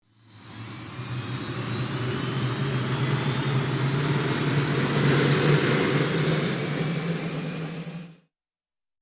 Turbine Fire Up 3
Turbine Fire Up 3 is a free nature sound effect available for download in MP3 format.
Turbine Fire Up 3.mp3